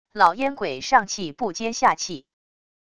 老烟鬼上气不接下气wav音频